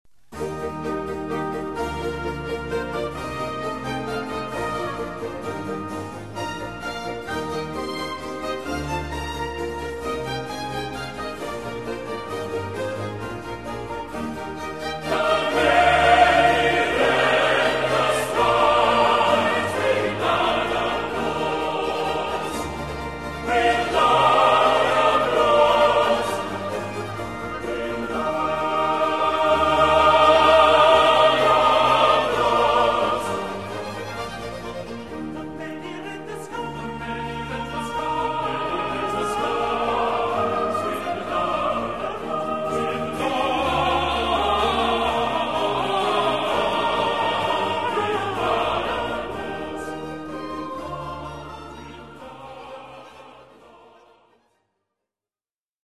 sopran
tenor
bass
Haapsalu Festivali Koor
Tallinna Barokkorkester